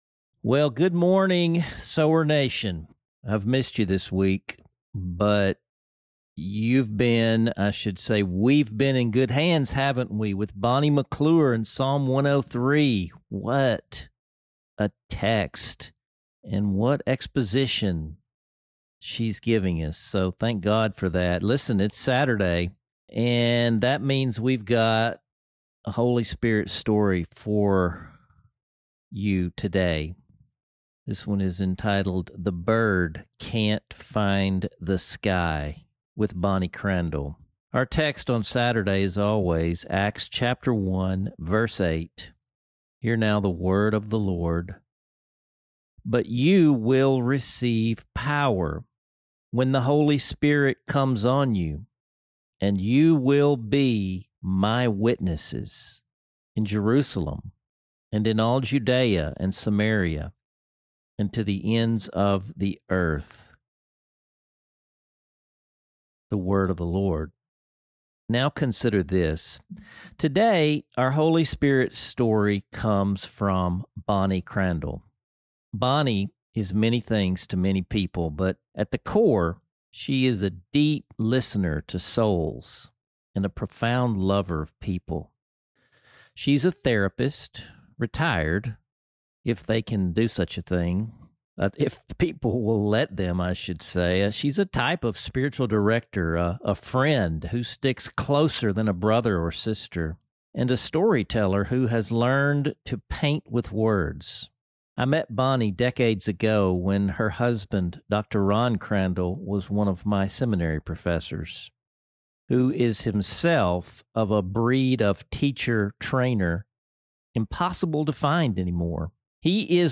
Below, you will read the story in her words, and, if you listen, you will hear it in her own voice.